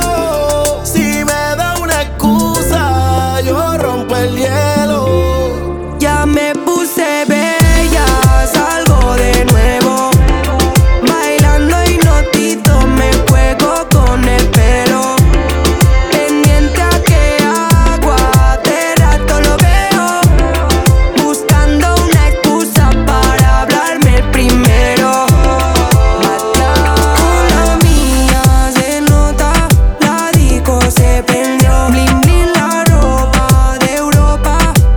Urbano latino
Жанр: Латино